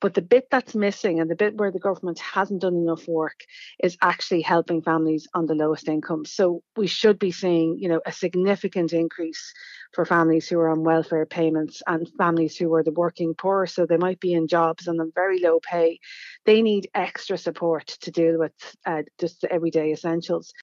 Spokesperson